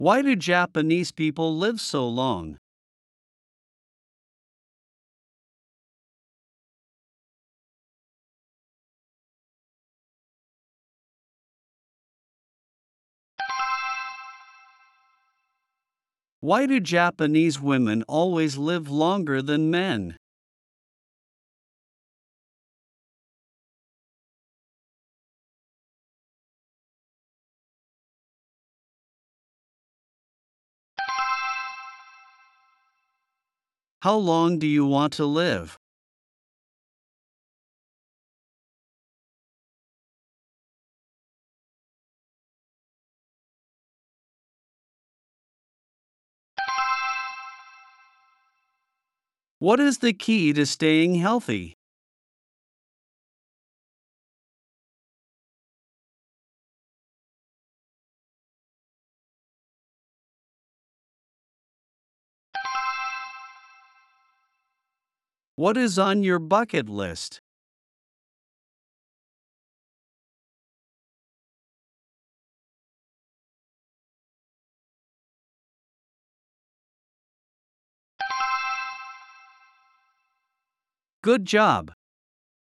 プレイヤーを再生すると英語で5つの質問が1問ずつ流れ、10秒のポーズ（無音部分）があります。
10秒後に流れる電子音が終了の合図です。
10秒スピーチ質問音声